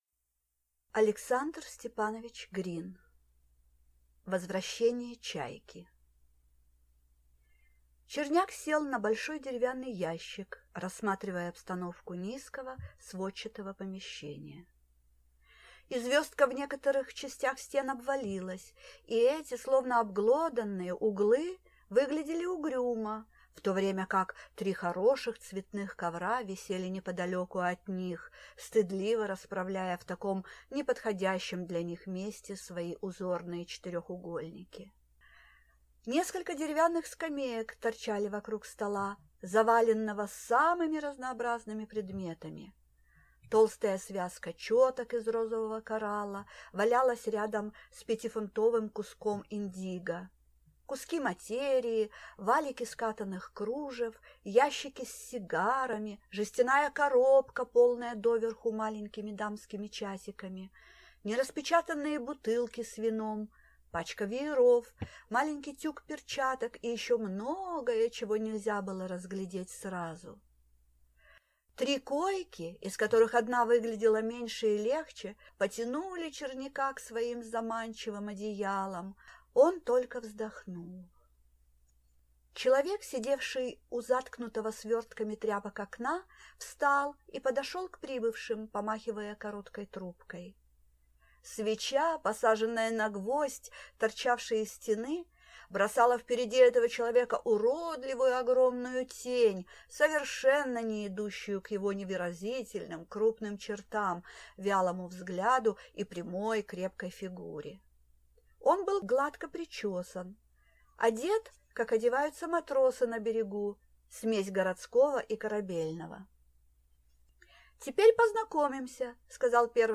Аудиокнига Возвращение «Чайки» | Библиотека аудиокниг